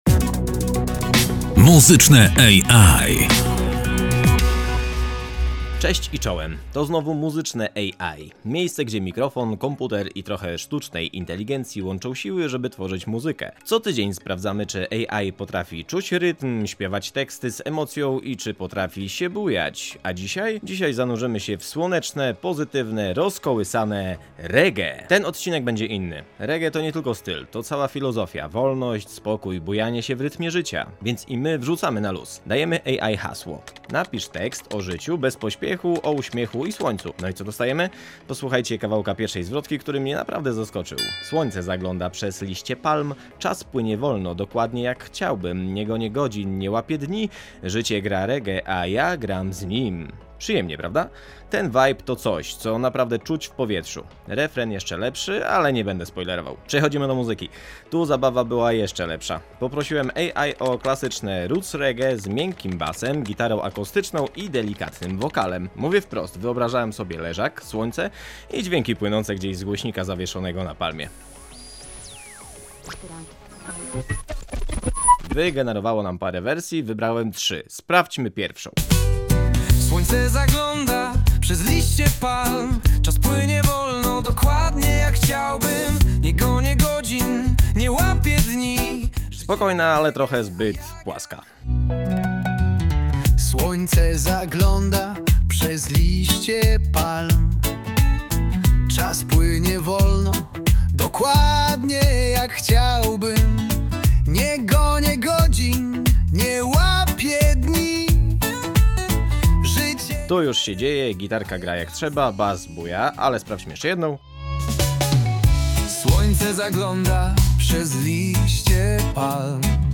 Odcinek 2 – REGGAE
W tym odcinku „Muzycznego EjAj” wrzucamy na luz i odpływamy w ciepłe, pozytywne brzmienia rodem z Jamajki.
Od pierwszej zwrotki przez bujający refren aż po instrumentalne solo – wszystko zostało wygenerowane, zredagowane i złożone w spójną całość przy współpracy człowieka i algorytmu.
EjAj-Reggae.mp3